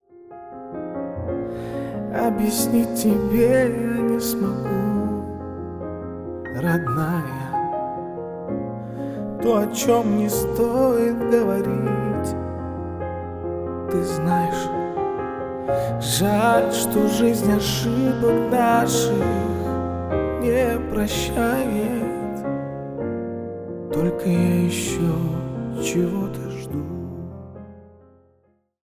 Поп Музыка
спокойные # тихие # грустные